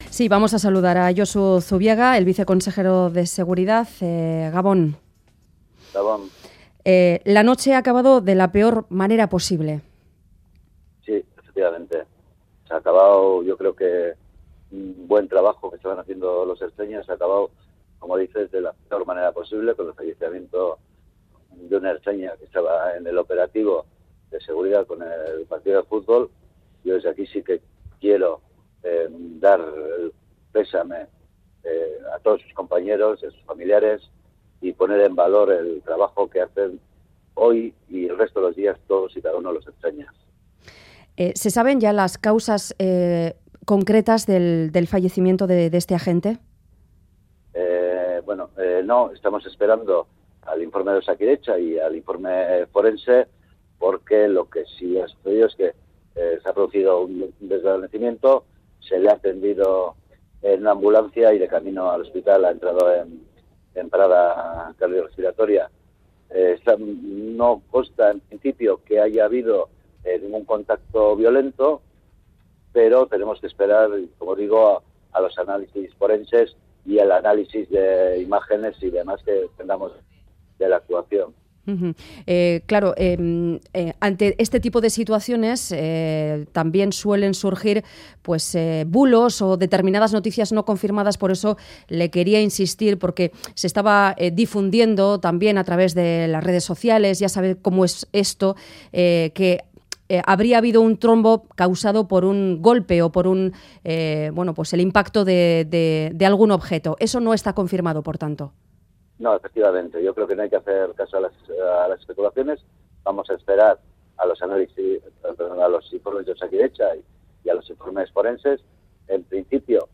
Audio: Entrevistado en Ganbara el viceconsejero de Seguridad, Josu Zubiaga, ha relatado que 'se ha producido un desvanecimiento, se le ha atendido en la ambulancia y de camino hacia el hospital ha entrado en parada'.
Radio Euskadi GANBARA 'No consta que haya habido ningún contacto violento' Última actualización: 22/02/2018 23:49 (UTC+1) Entrevista al viceconsejero de Seguridad del Gobierno Vasco, Josu Zubiaga.